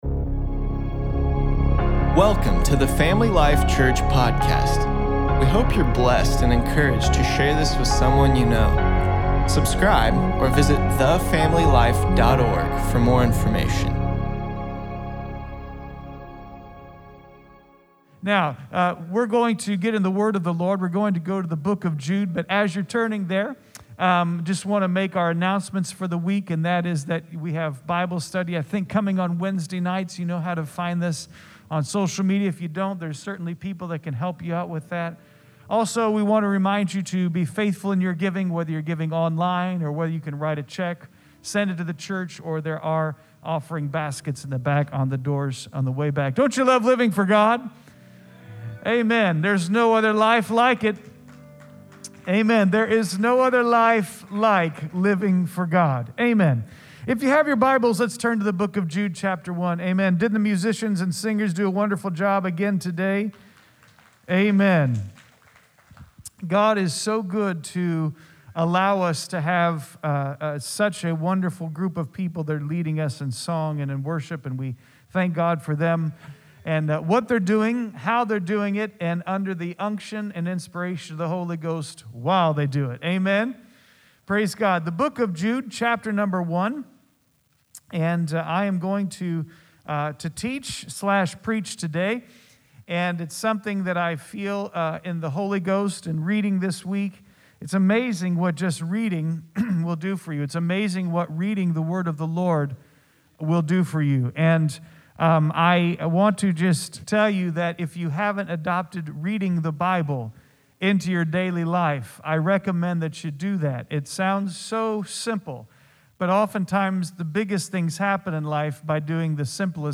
8.9.20_sermon_p.mp3